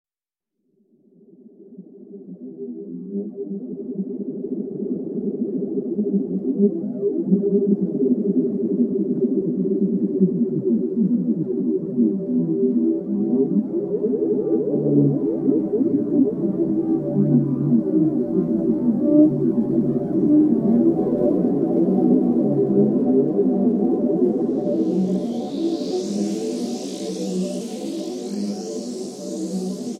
Acoustic, Electric Guitar and SuperCollider